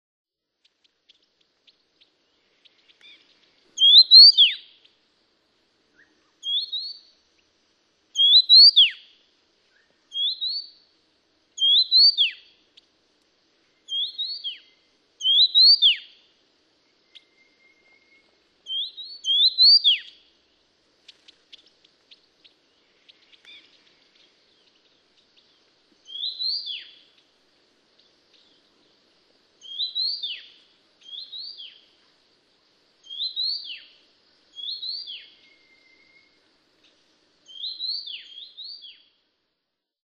從清晨到黃昏的六段充滿鳥聲的自然錄音，搭配上27種鳥聲，最後以夜晚的貓頭鷹聲音做結尾，是最值得珍藏的自然聲音CD。